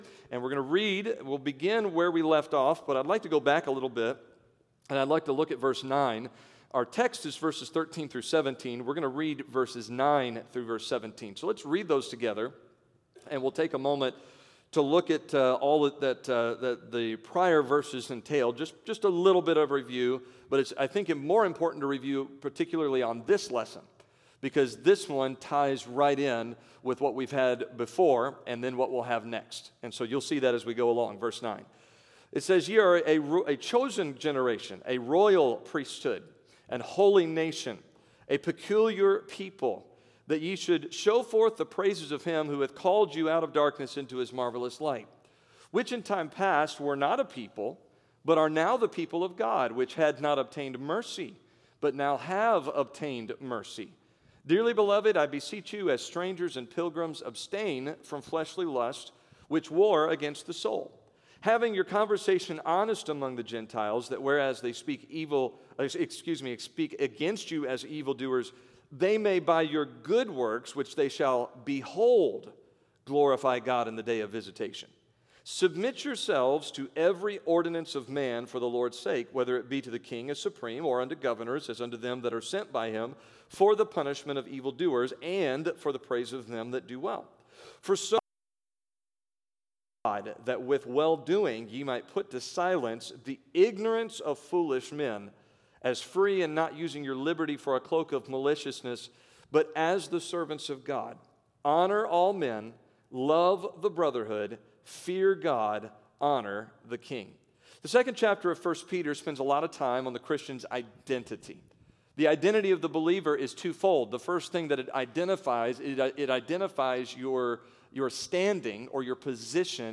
October 2022 A Study in 1 Peter Wednesday Evening A Study in 1 Peter Scripture: 1 Peter 2:13-17 Download: Audio